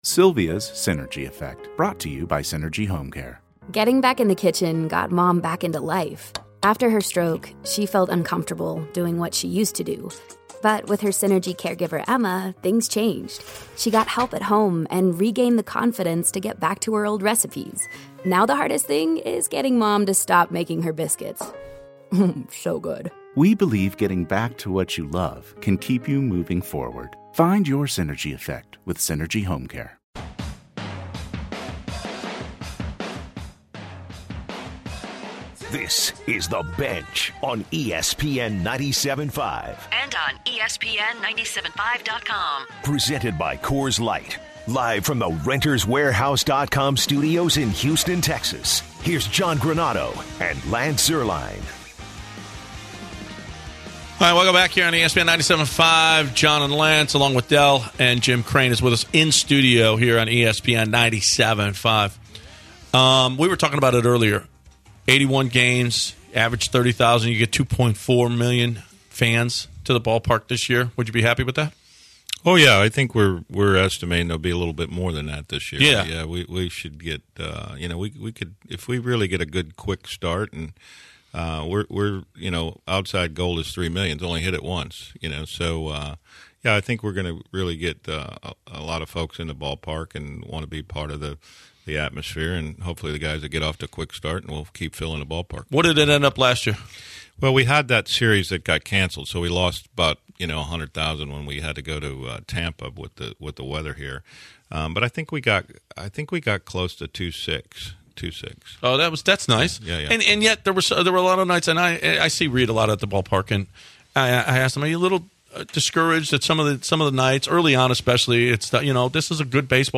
In the second hour, Jim Crane stays with the guys in the studio as they discuss some of the behind-the-scenes action from the last season. As the show rolls on, they preview the season ahead with Gerrit Cole in the fold, discuss some of the contract situations with the team’s young stars, and talk about the impact the analytics movement has had on the team.